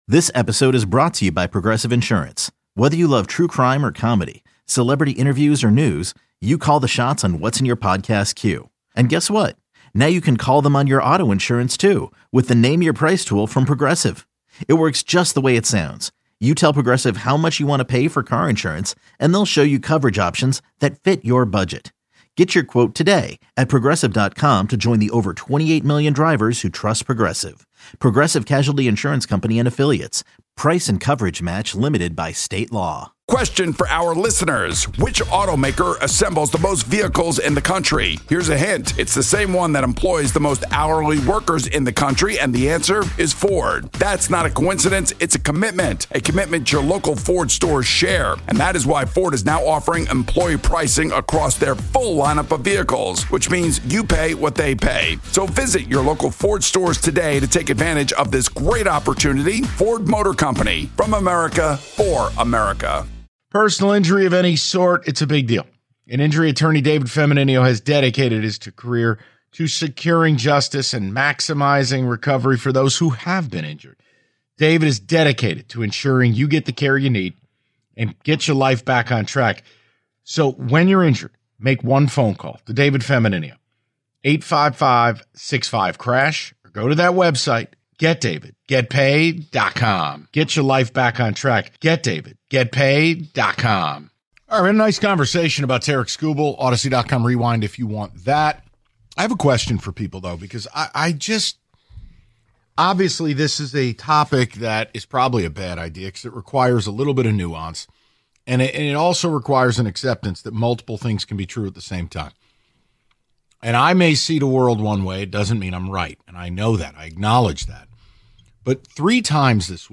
HOUR 4: Final Thoughts + Calls On Lions. Final Takeaways From Lions' Win Over The Bears.
Sports